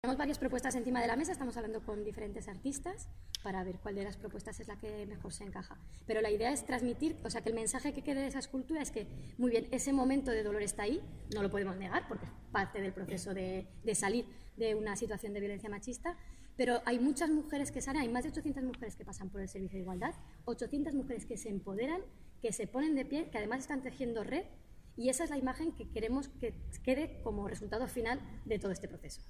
Declaraciones de la concejala de Educación e Inclusión, Arantza Gracia, sobre el Memorial contra la violencia machista